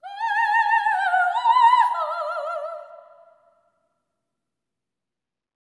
OPERATIC11.wav